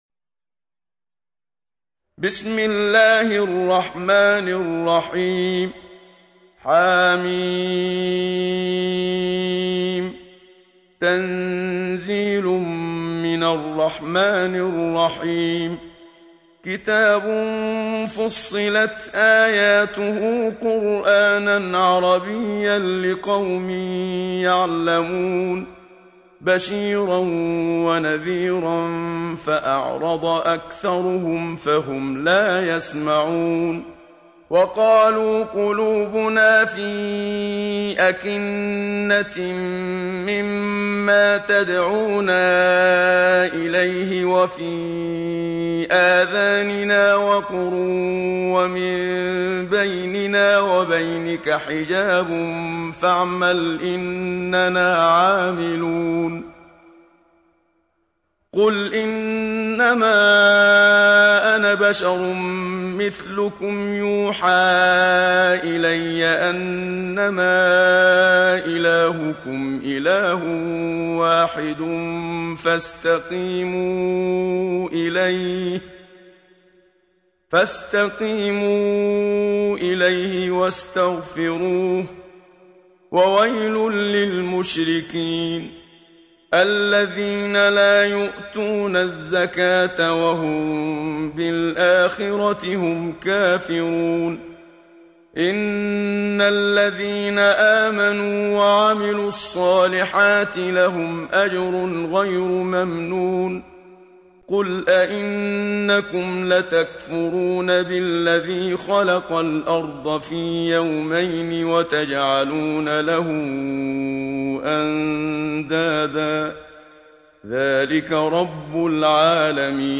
سورة فصلت | القارئ محمد صديق المنشاوي